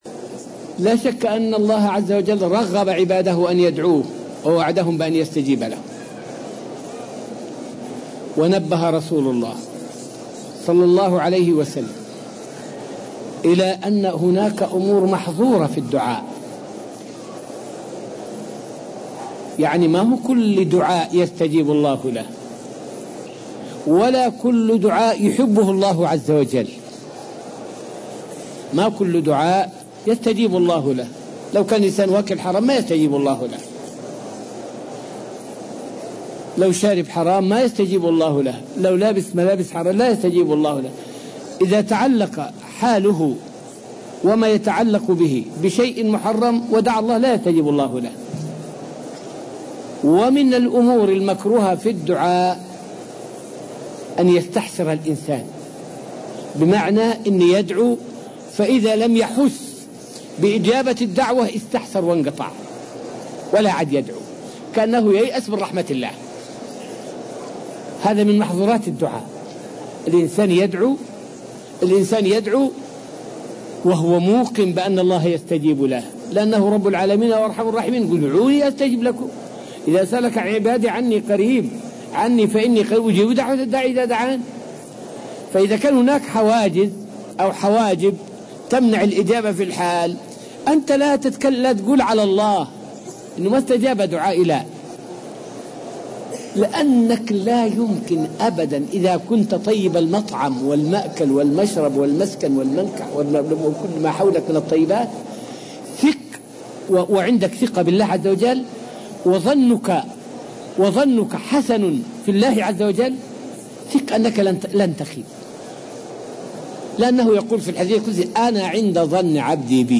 فائدة من الدرس الخامس والعشرون من دروس تفسير سورة البقرة والتي ألقيت في المسجد النبوي الشريف حول محظورات الدعاء.